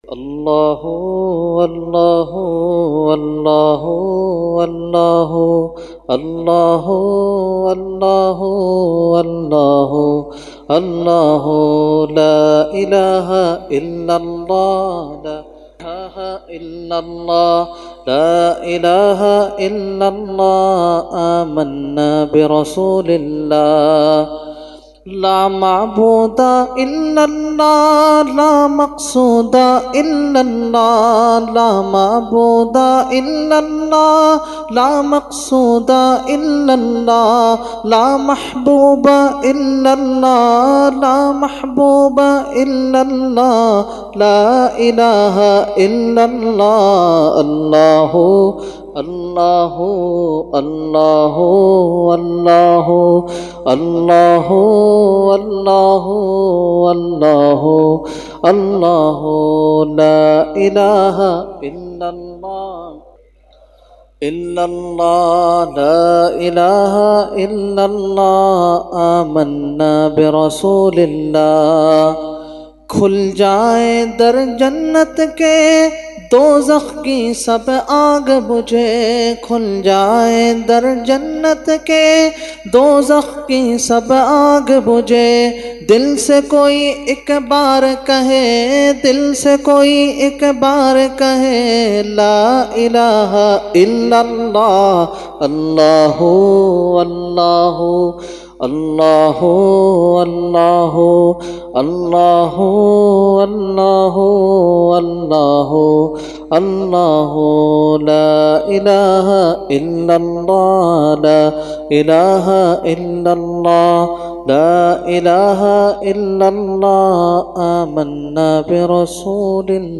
Category : Hamd | Language : UrduEvent : 11veen Shareef 2019